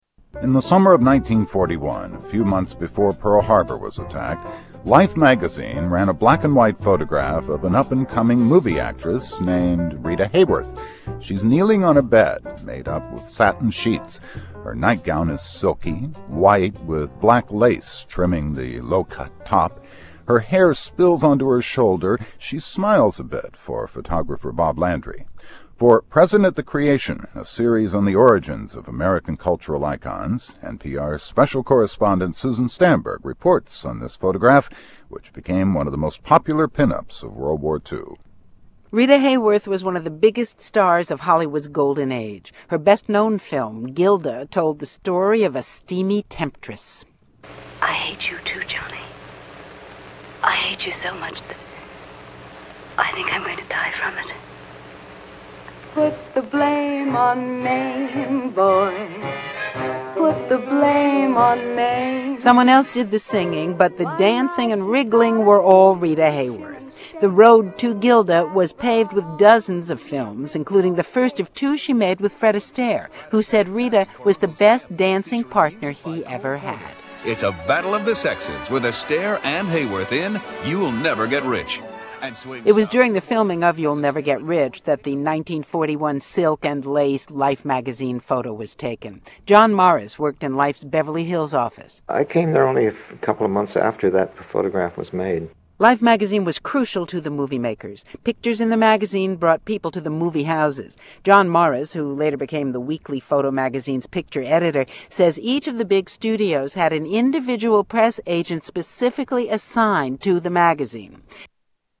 NPR report on Rita Hayworth 1